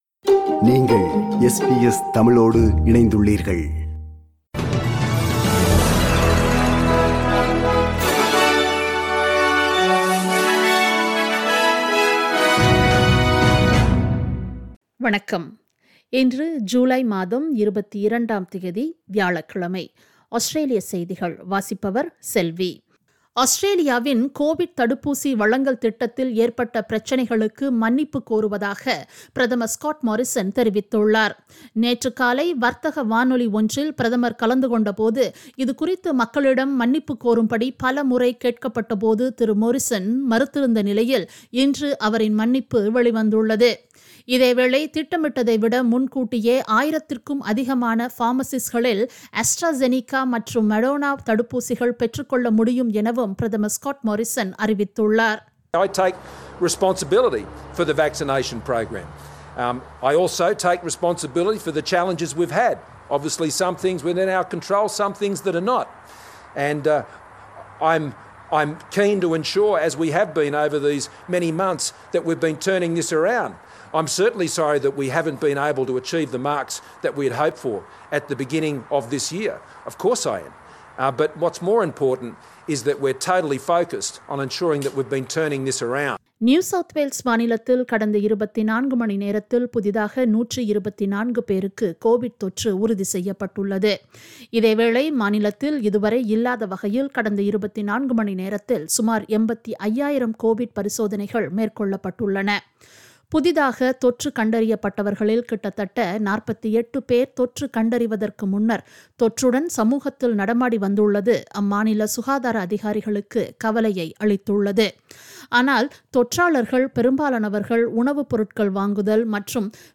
Australian news bulletin for Thursday 22 July 2021.